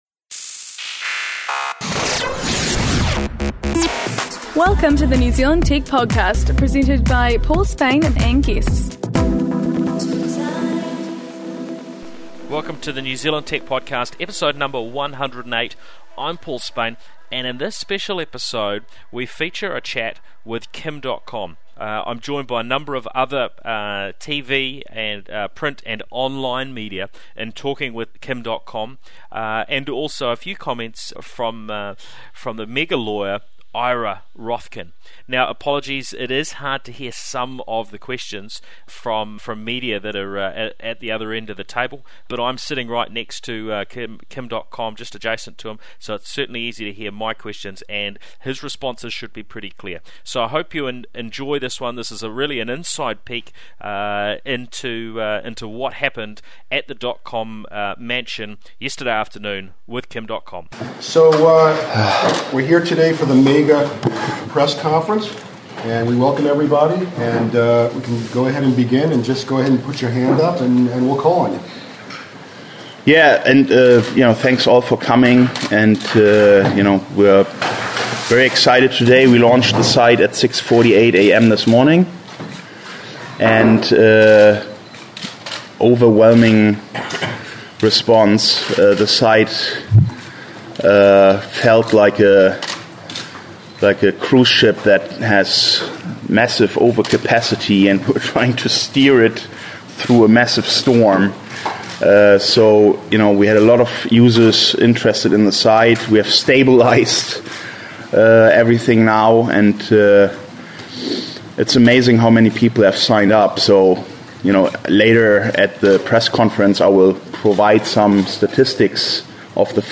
Dotcom does 95% of the talking.